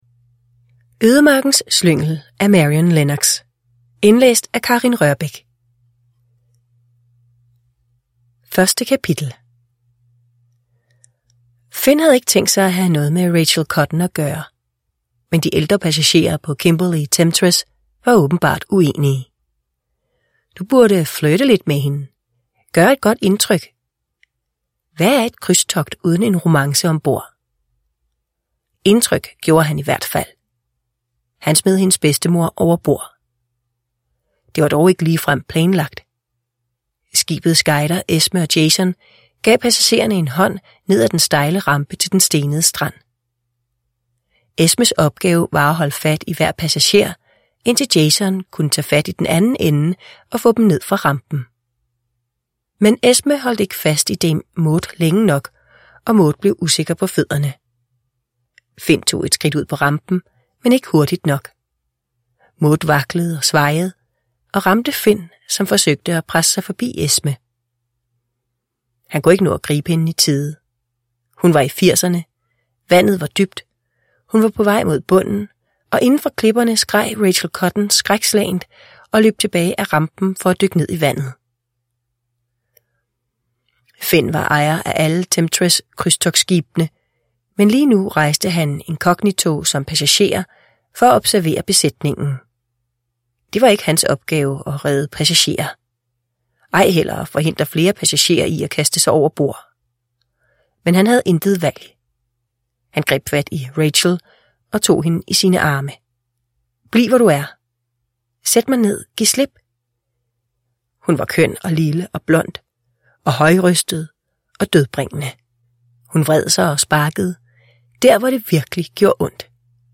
Ødemarkens slyngel – Ljudbok